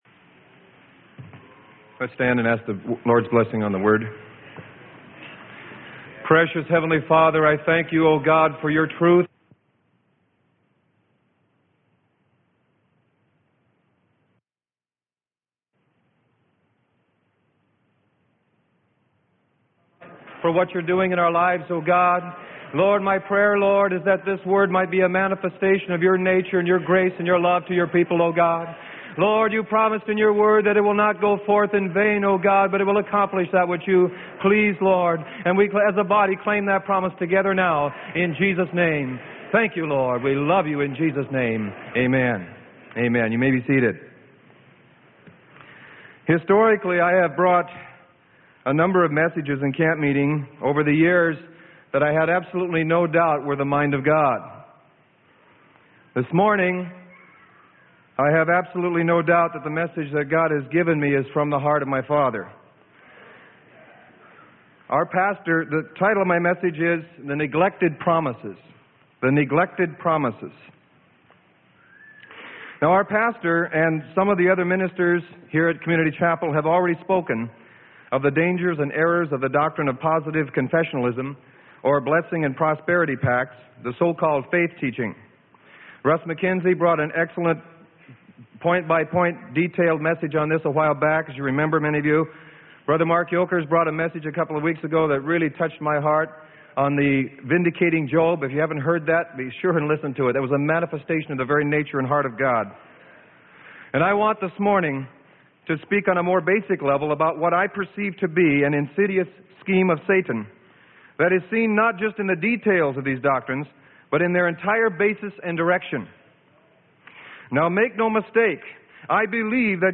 Sermon: THE NEGLECTED PROMISES - Freely Given Online Library